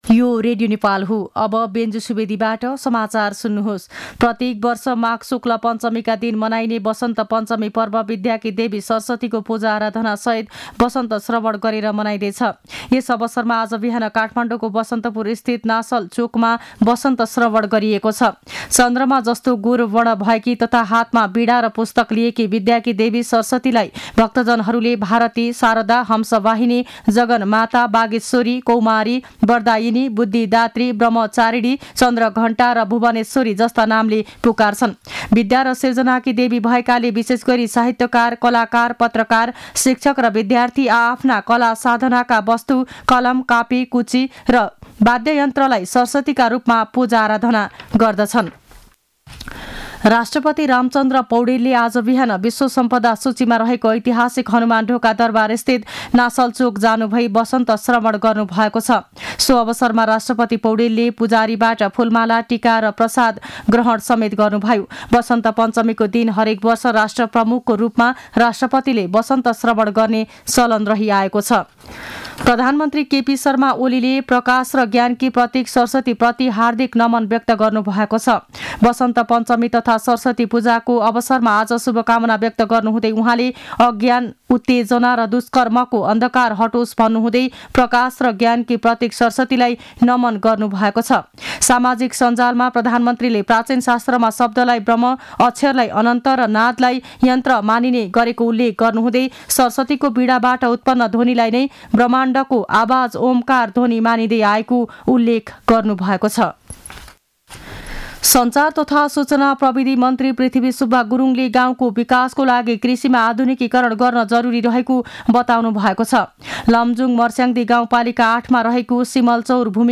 मध्यान्ह १२ बजेको नेपाली समाचार : २२ माघ , २०८१